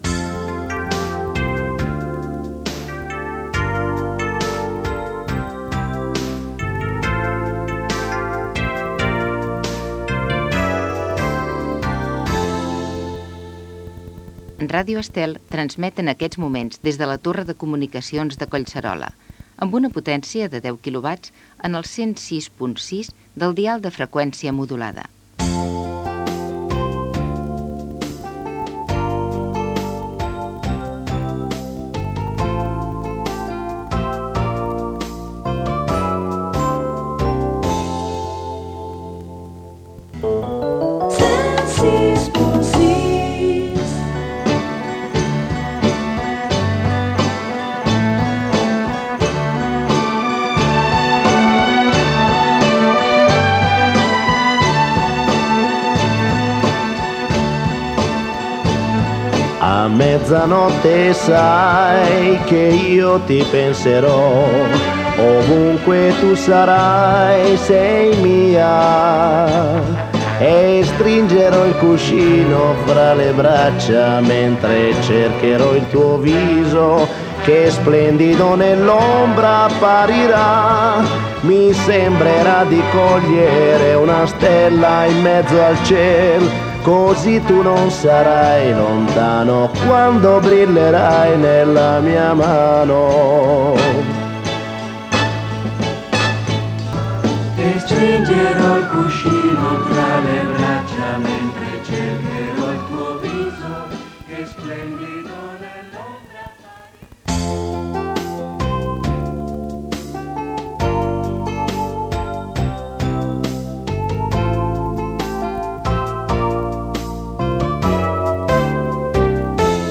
Sintonia, identificacions, música i avís de l'inici de la programació en proves des de la Torre de Comunicacions de Collserola.
Musical
Primer dia d'emissió des de la Torre de Comunicacions de Collserola.